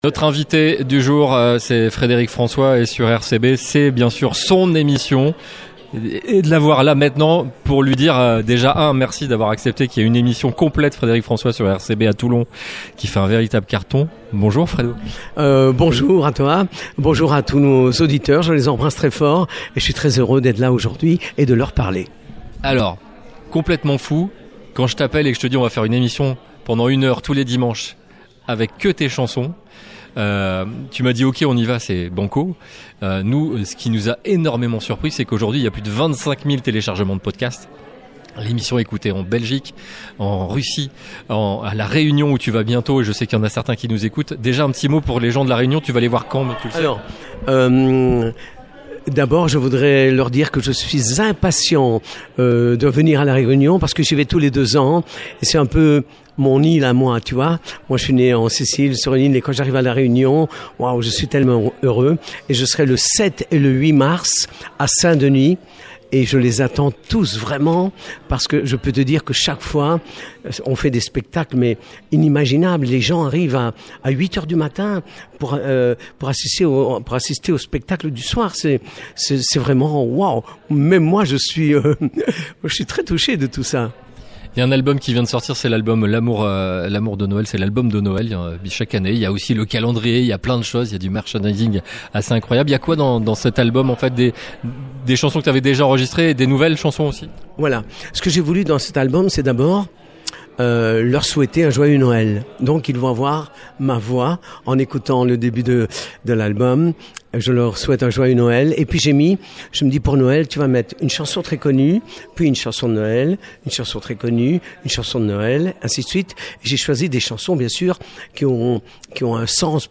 FREDERIC FRANCOIS : Son interview de Noel